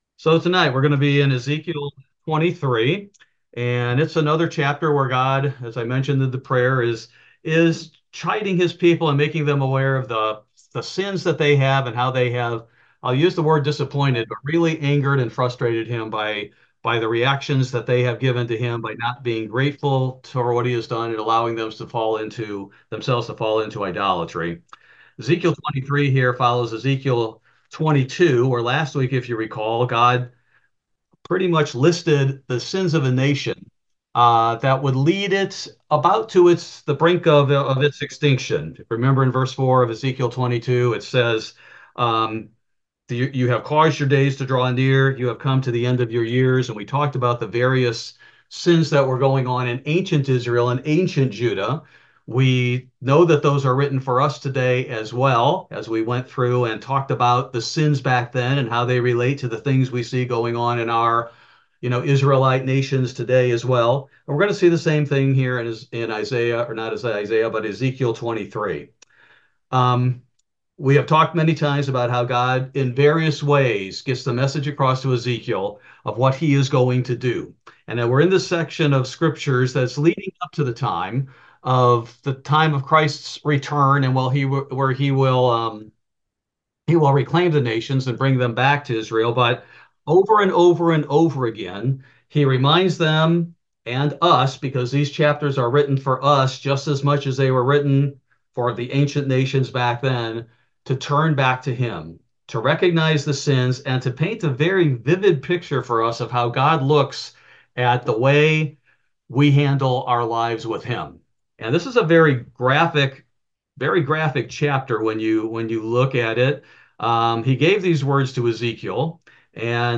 Bible Study: November 20, 2024